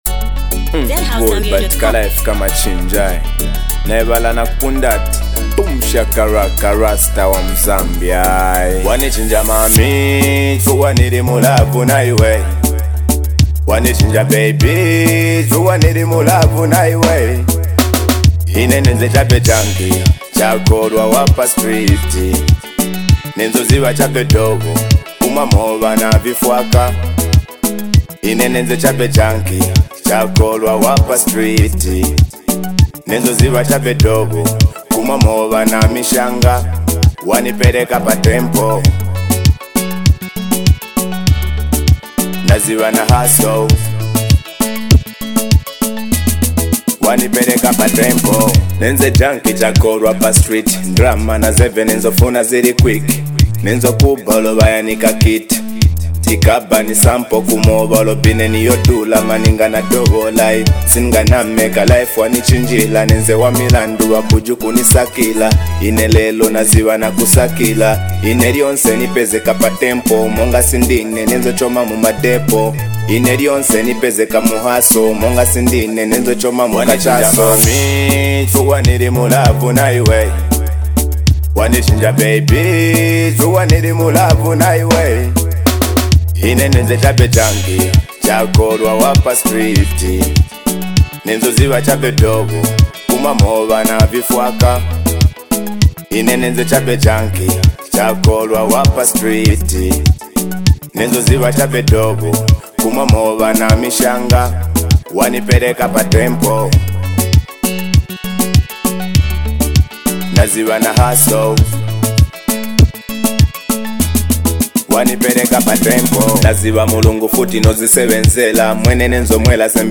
a smooth and heartfelt track that speaks to the soul.